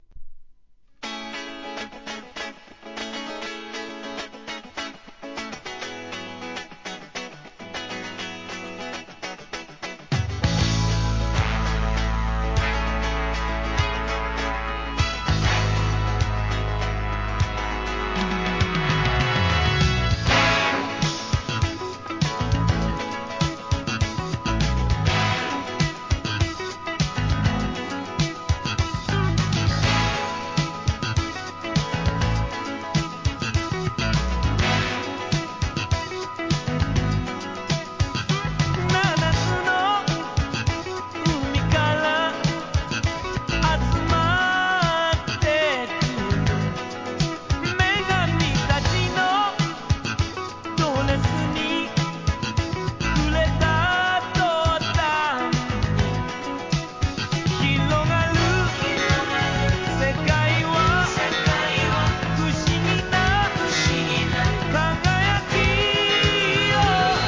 ¥ 2,750 税込 関連カテゴリ SOUL/FUNK/etc...